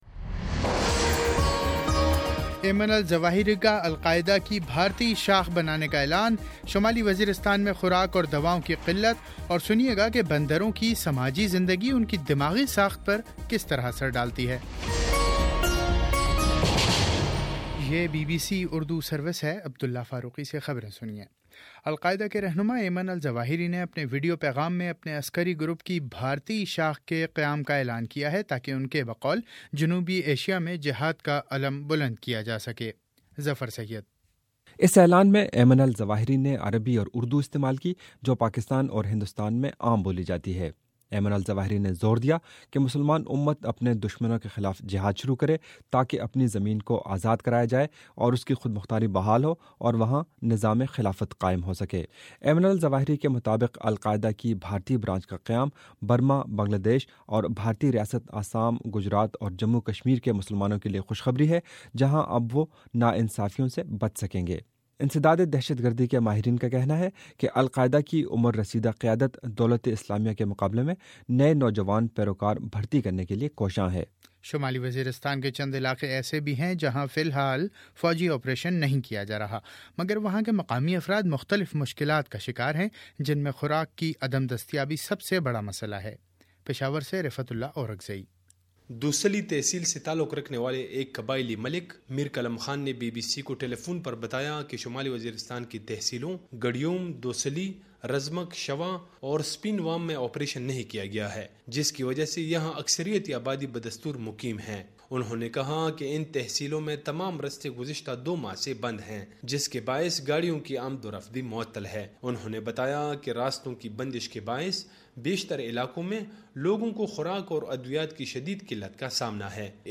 چار ستمبر : صبح نو بجے کا نیوز بُلیٹنں
دس منٹ کا نیوز بُلیٹن روزانہ پاکستانی وقت کے مطابق صبح 9 بجے، شام 6 بجے اور پھر 7 بجے۔